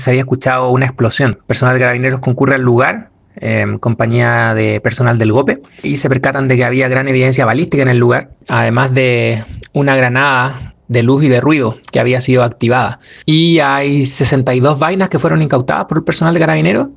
cu-fiscal-olivari-balacera-recoleta.mp3